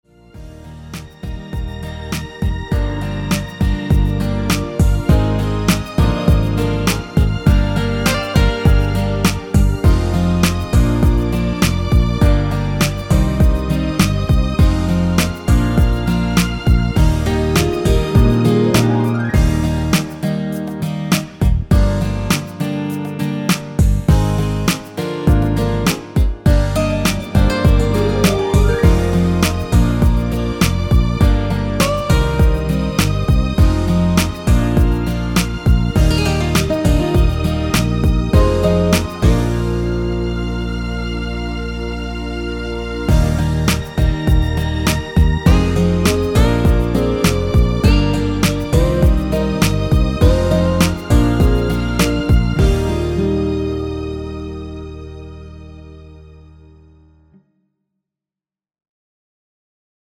1절후 클라이 막스로 바로 진행되며 엔딩이 너무 길어 4마디로 짧게 편곡 하였습니다.
Eb
앞부분30초, 뒷부분30초씩 편집해서 올려 드리고 있습니다.
중간에 음이 끈어지고 다시 나오는 이유는